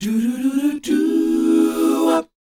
DOWOP F#4A.wav